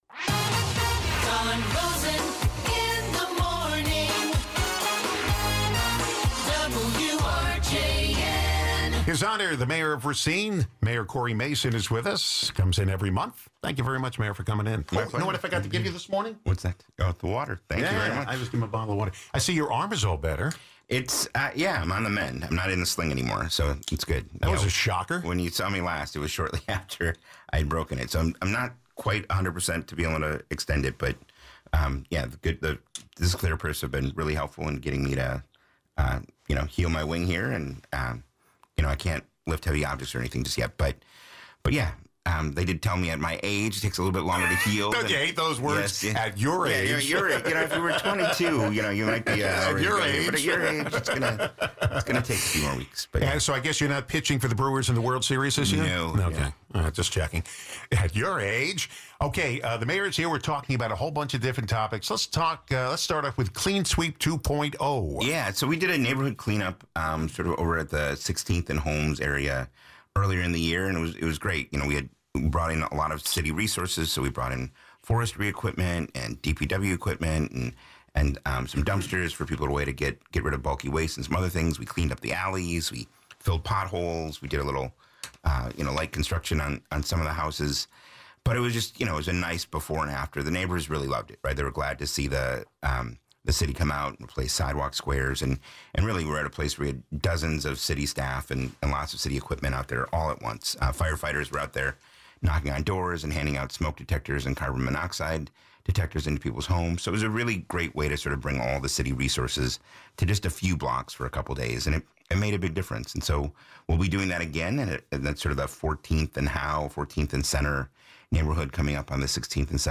City of Racine Mayor Cory Mason discusses a number of issues of concern and importance to Racine residents.
Talking with Mayor Mason Guests: Cory Mason